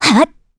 Valance-Vox_Attack2_kr.wav